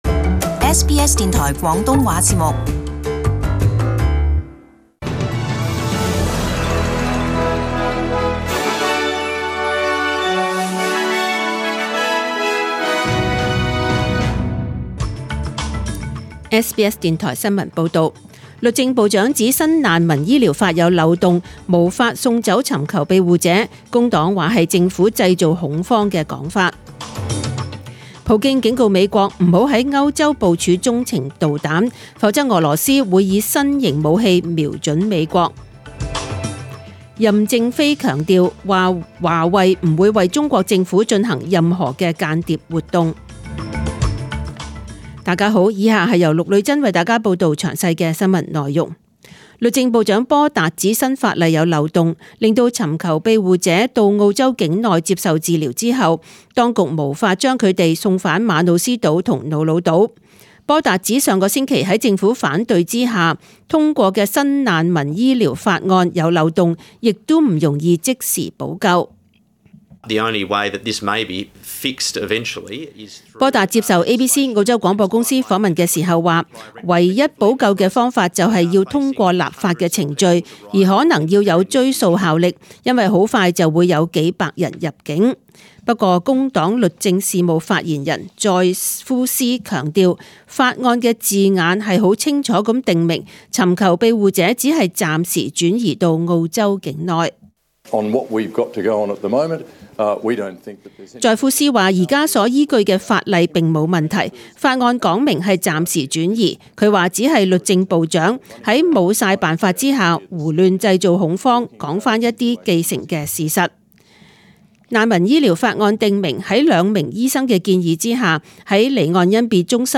Chinese (Cantonese) News Source: SBS News